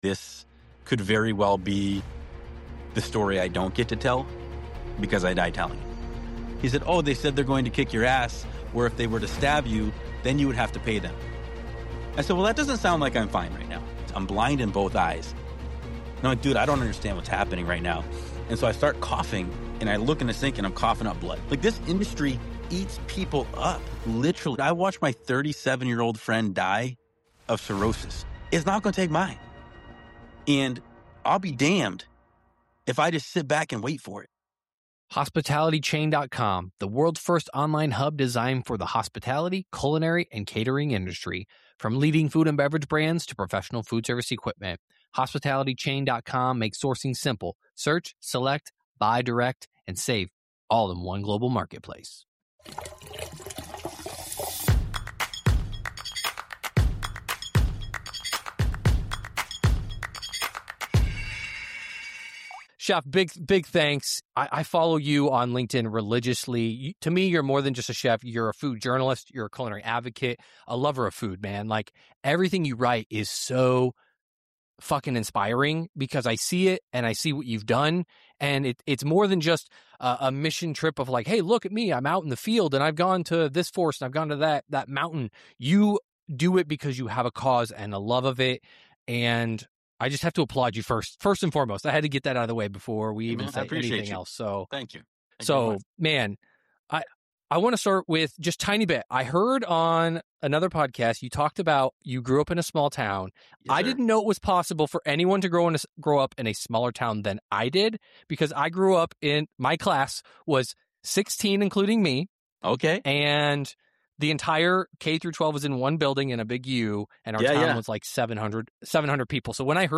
This conversation goes everywhere.